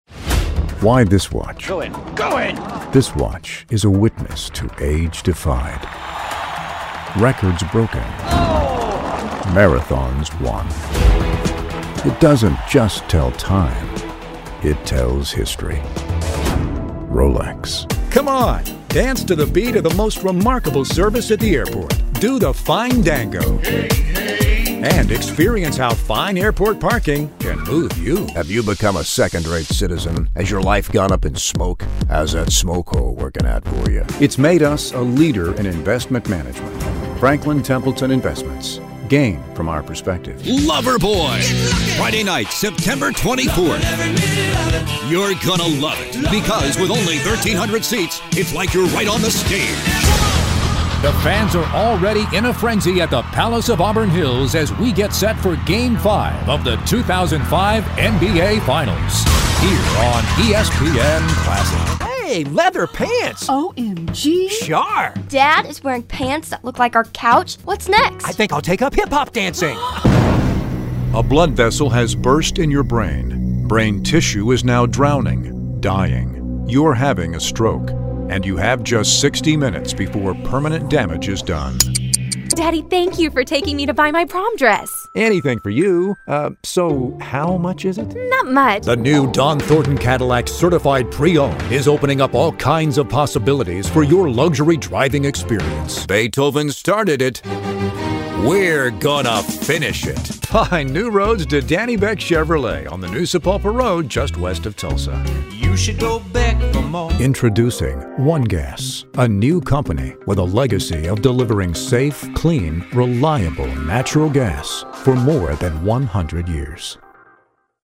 Commercial Demo 2 Minutes